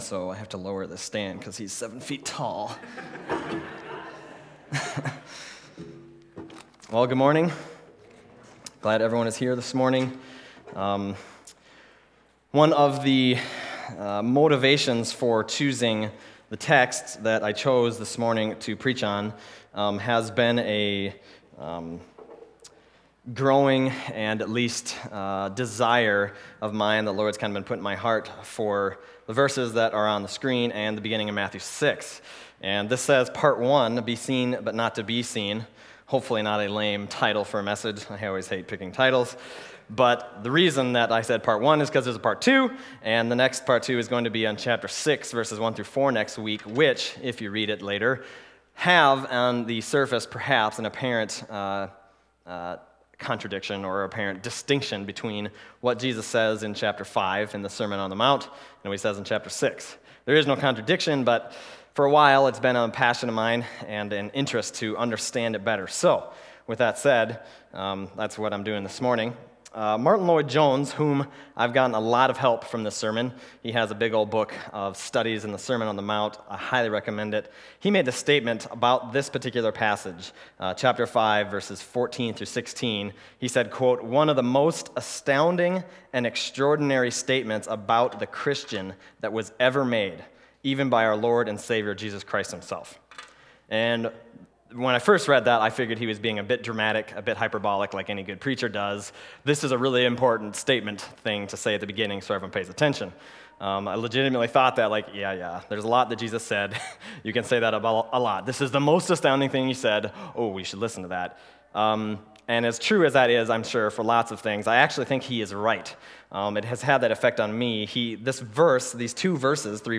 Passage: Matthew 5:14-16 Service Type: Sunday Morning